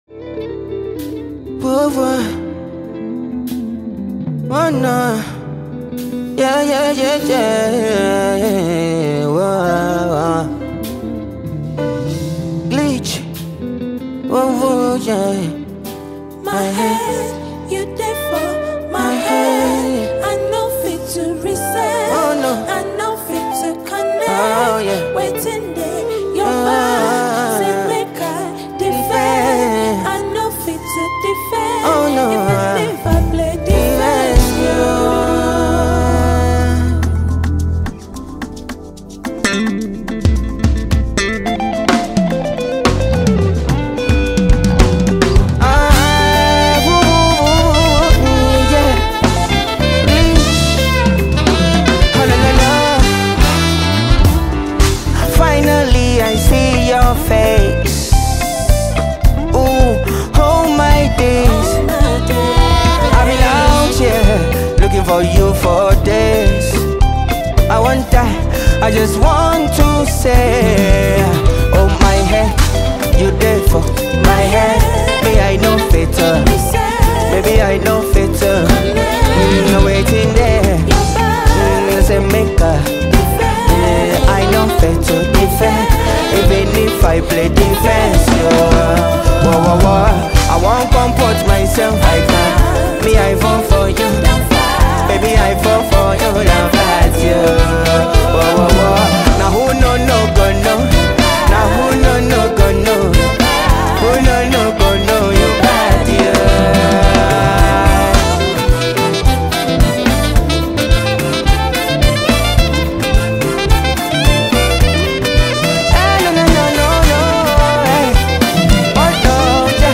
Ghanaian Afrobeat singer and songwriter